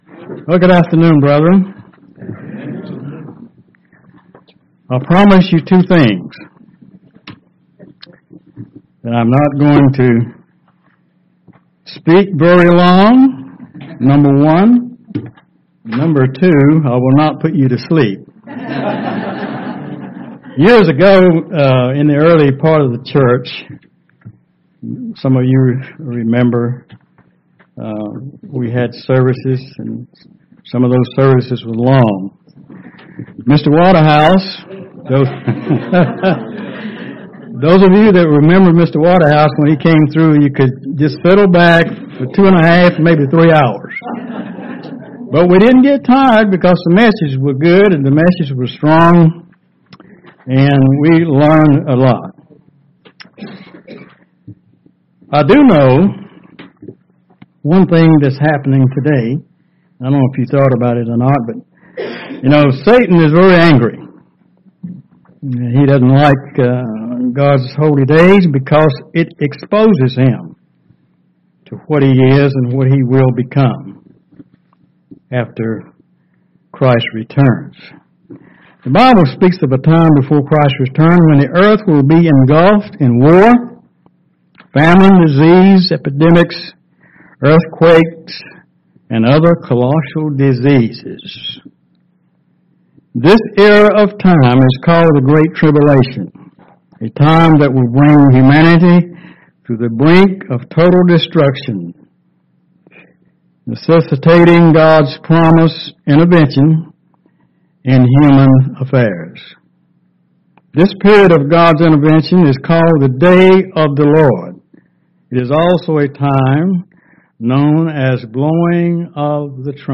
Sermons
Given in Charlotte, NC